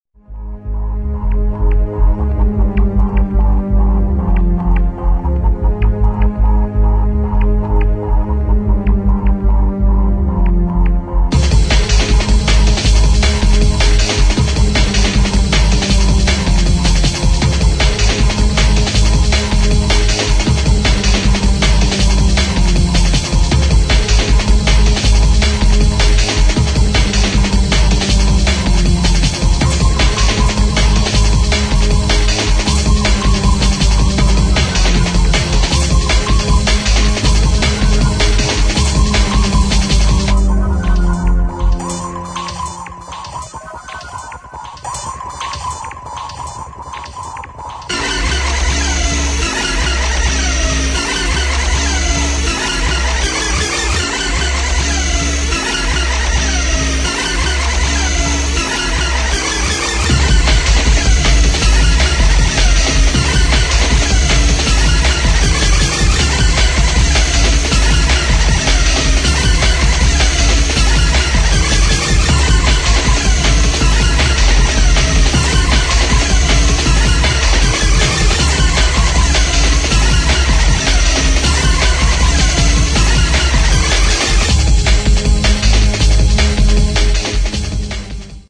[ DRUM'N'BASS | JUNGLE ]